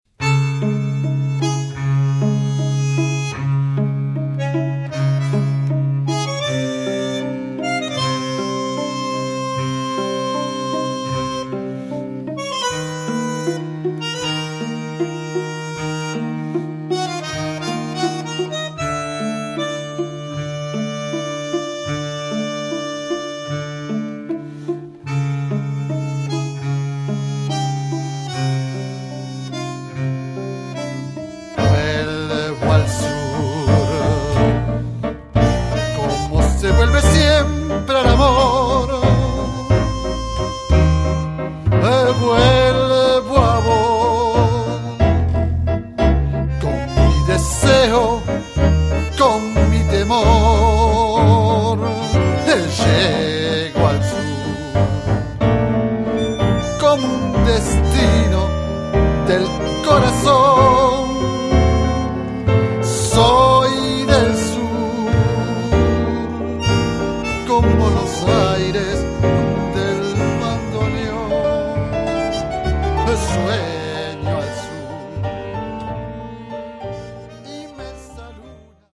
fonctionne actuellement comme un quintette de jazz
Guitare/chant/arrangement
Piano
Bandonéon
Violon alto
Contrebasse